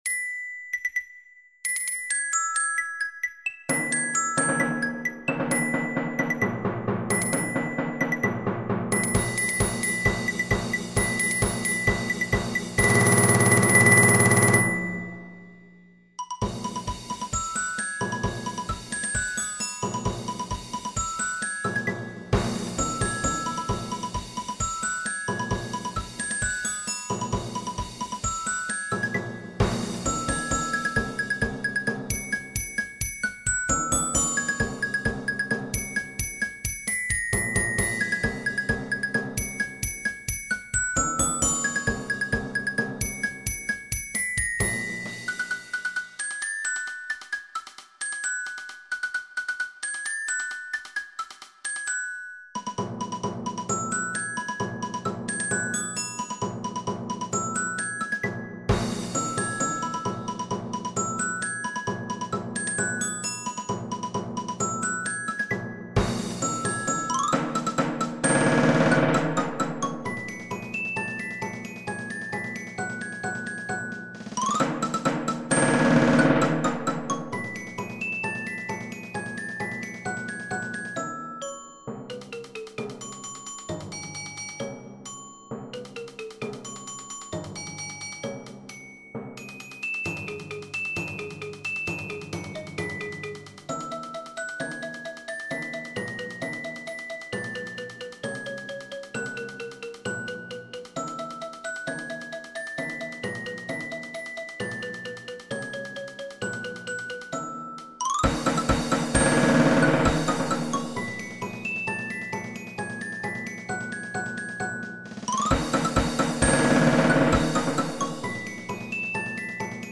Percussion ensemble percussion sextet